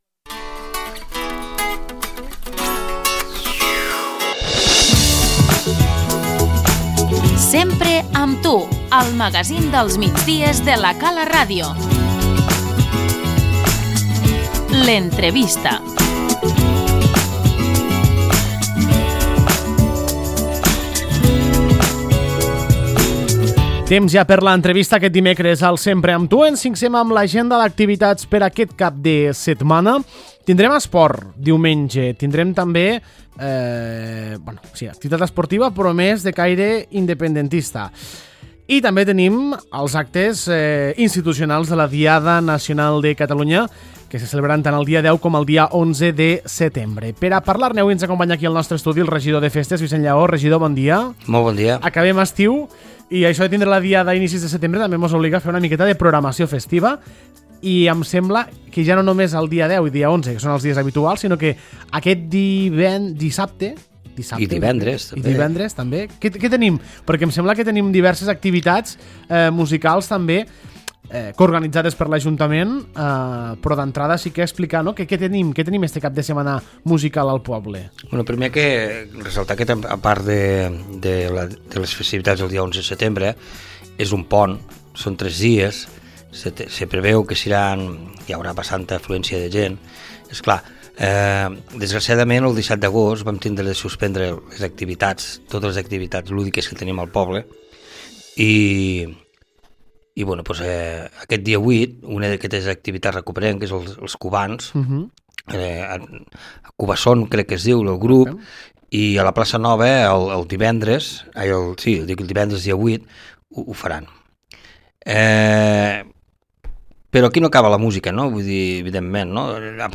L'entrevista - Vicenç Llaó, regidor de Cultura i Festes